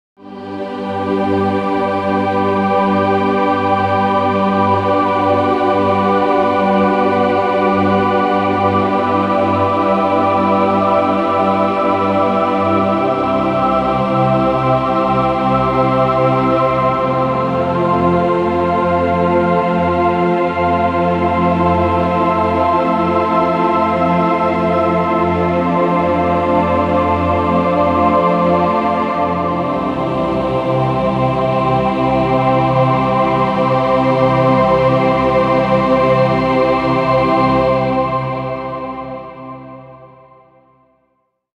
Genres: Background Music
Tempo: 116 bpm